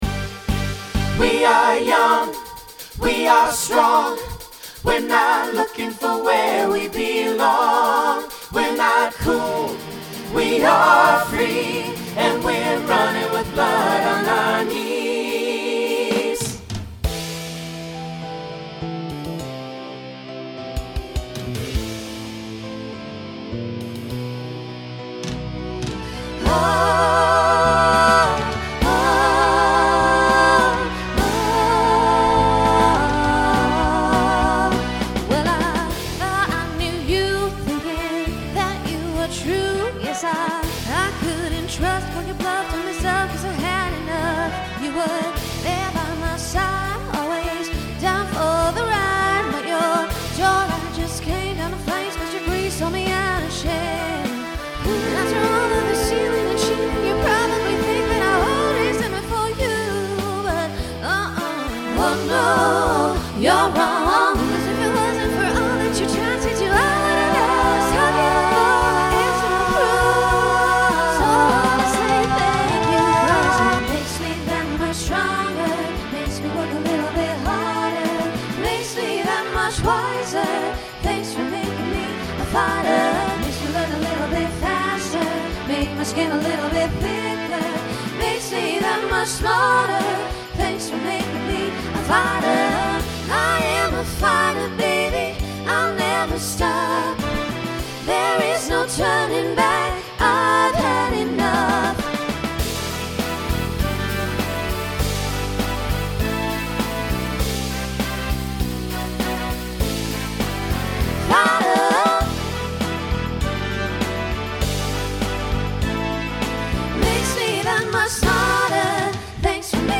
Starts SATB, then SSA, TTB, and finishes SATB.
Voicing Mixed
Pop/Dance